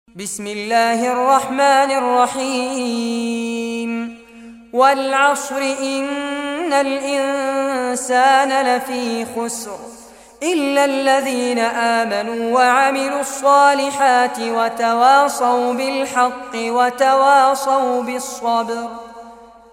Surah Asr Recitation by Fares Abbad
103-surah-asr.mp3